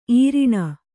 ♪ īriṇa